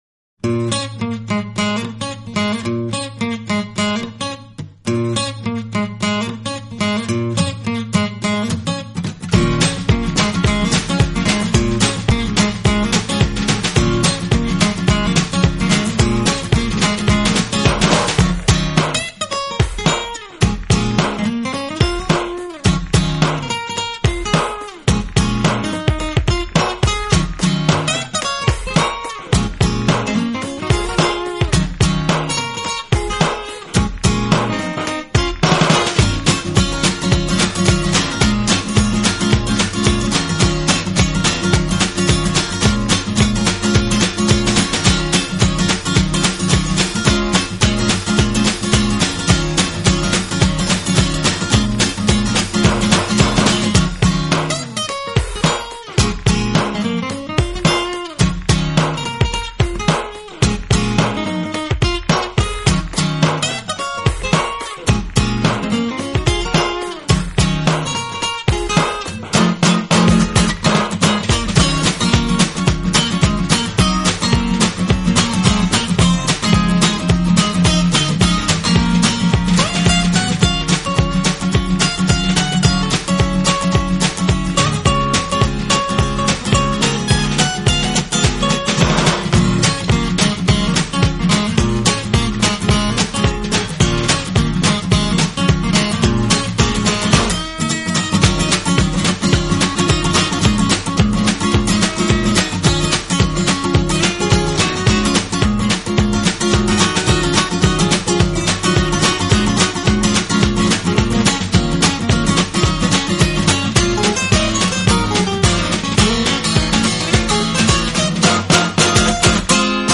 是配乐也是流行乐！用空心吉它大玩摇滚！能够舒缓心灵却又时髦不已！